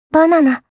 001_Anneli（上機嫌）_ばなな.wav